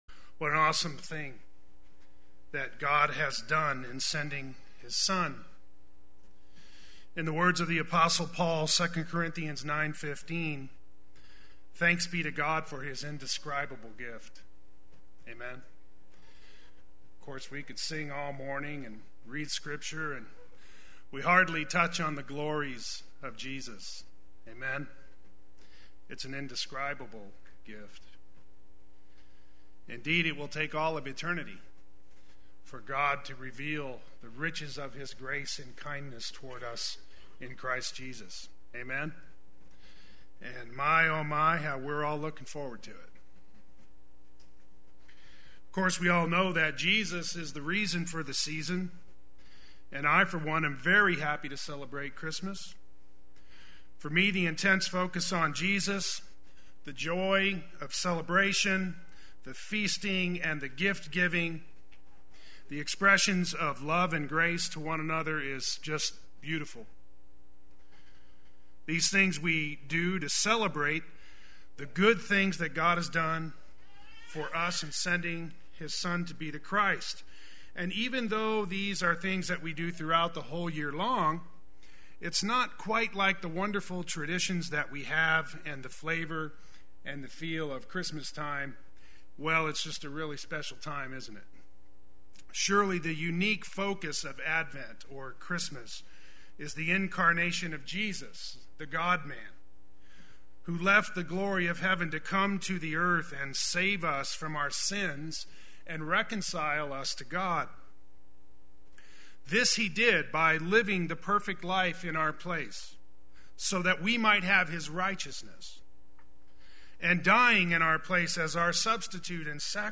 Play Sermon Get HCF Teaching Automatically.
Christmas Gospel Sunday Worship